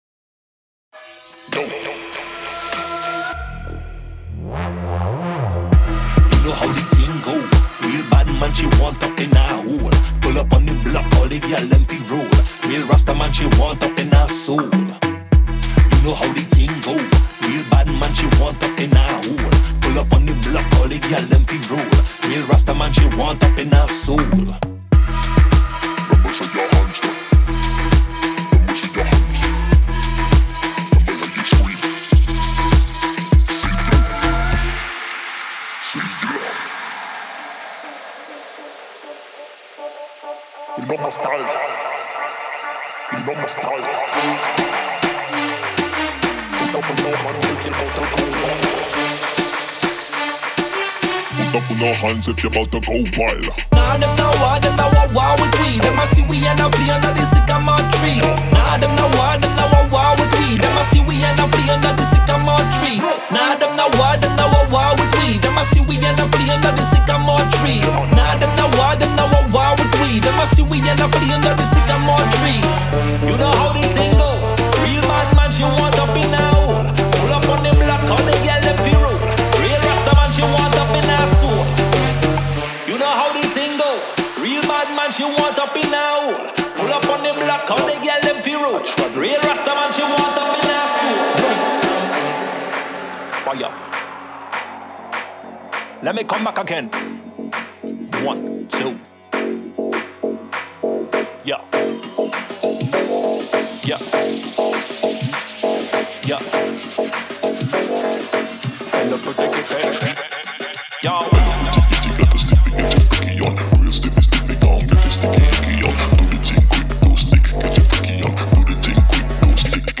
文化，时间和节奏交织在一起。一切都相互联系，用民族色彩绘画，并在脉动的凹槽下流动。
100 BPM
.50 Drum &Percussions Loops
.15 Vocal Hooks